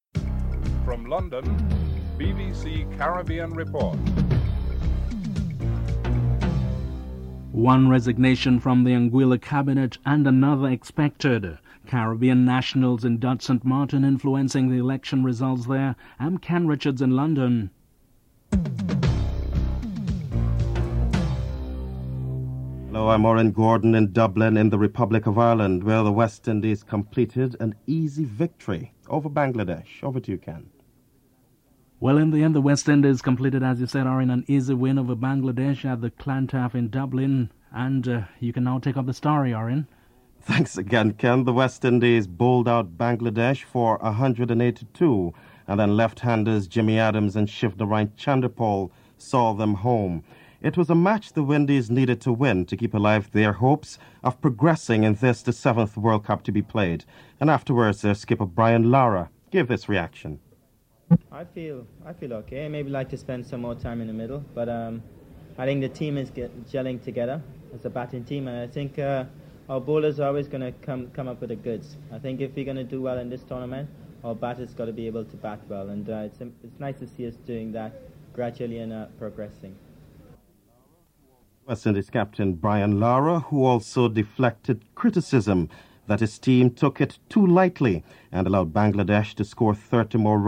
Minister Hubert Hughes discusses the breakdown of the coalition and the effects on the political climate in Anguilla. Victor Banks discusses his resignation (05:20 - 9:00)